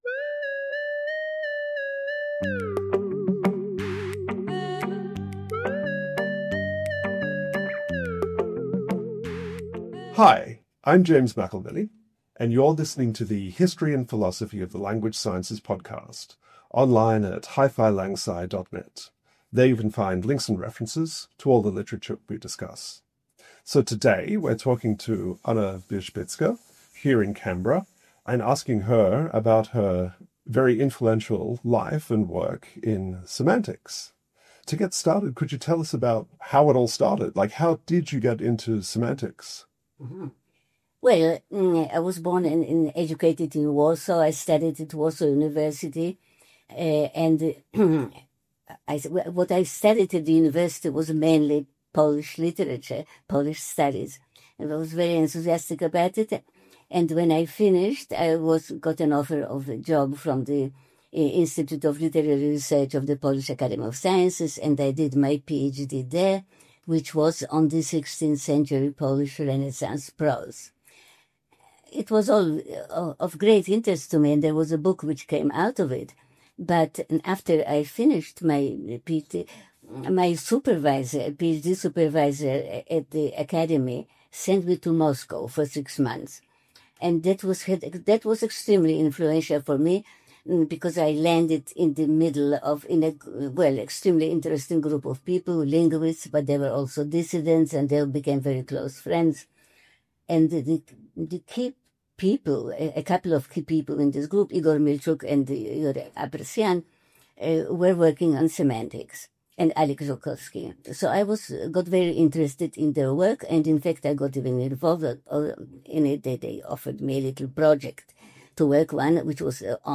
In this interview, we talk to Anna Wierzbicka about her life and research into semantics.